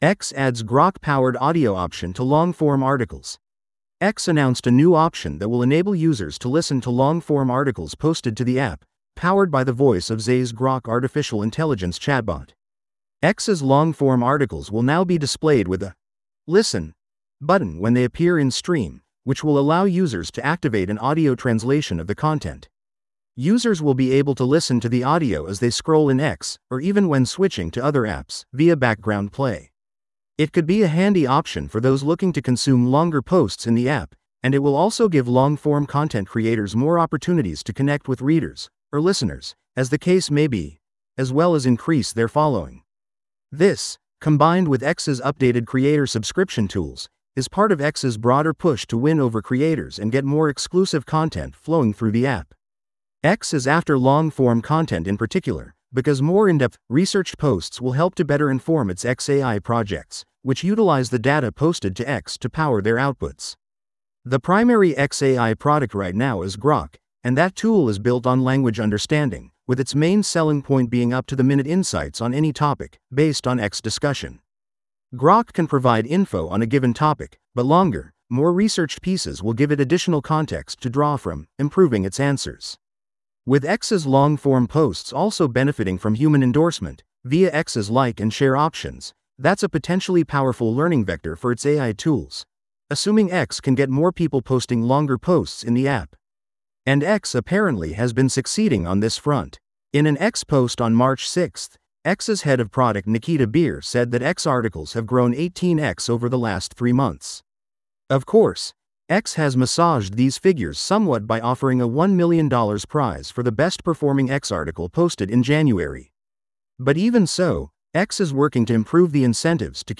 This audio is auto-generated.
x-formerly-twitter-adds-ai-powered-audio-reading-long-form-articles.wav